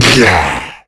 tongue_launch_01.wav